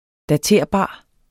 Udtale [ daˈteɐ̯ˀˌbɑˀ ]